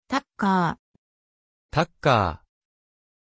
Aussprache von Tucker
pronunciation-tucker-ja.mp3